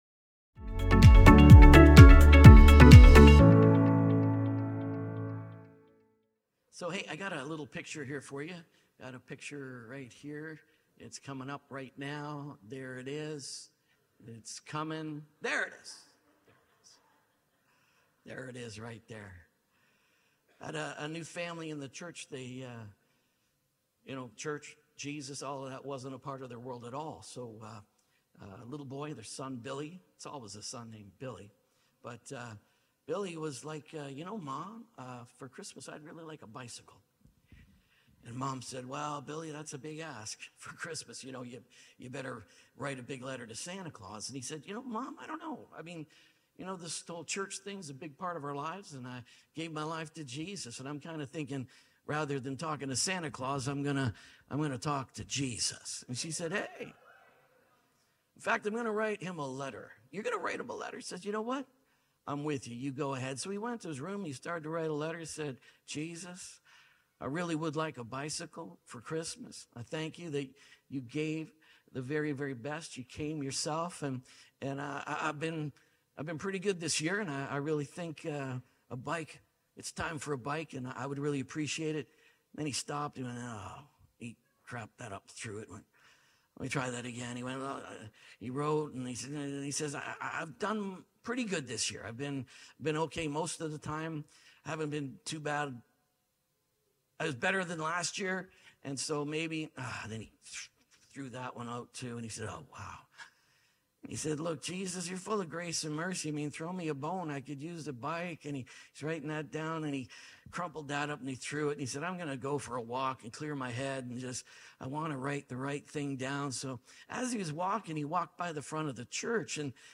Restored Dignity | INCARNATION Series | SERMON ONLY.mp3